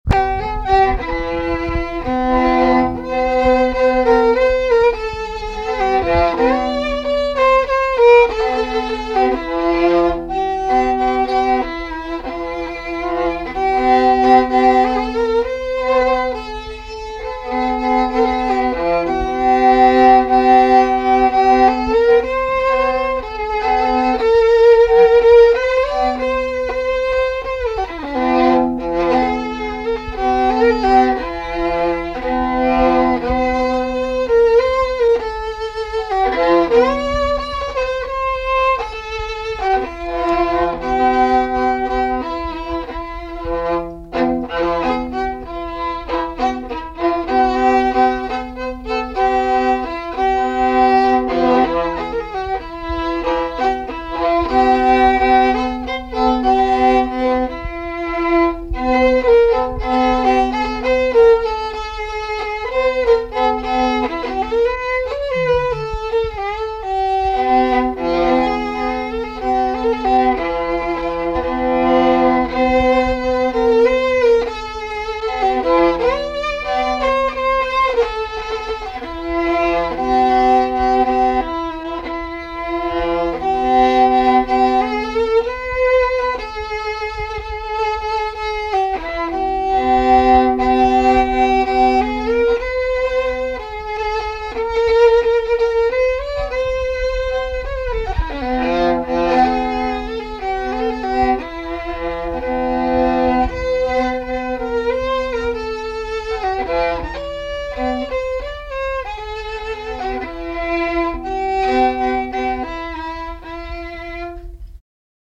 Résumé instrumental
Répertoire de musique traditionnelle
Pièce musicale inédite